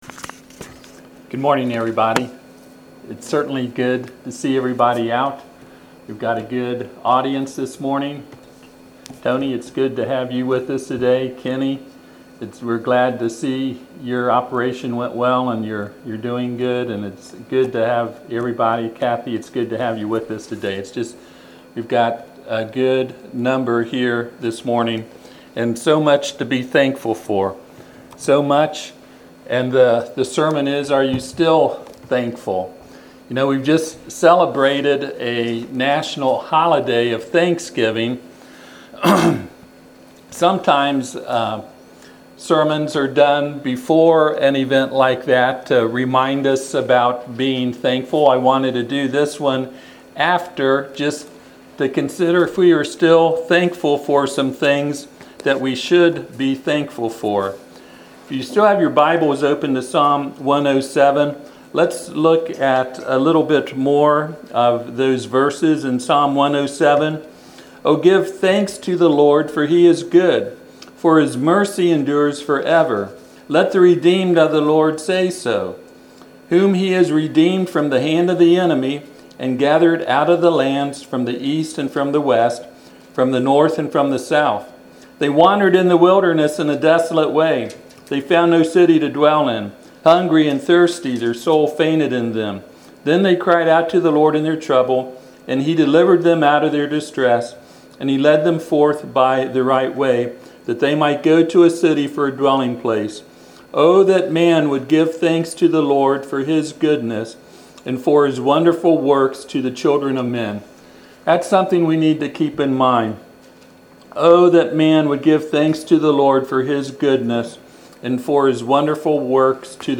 Psalm 107:1-2 Service Type: Sunday AM « Hades